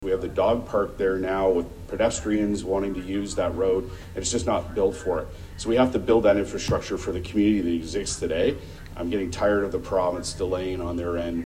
Councillor Paul Carr had this to say.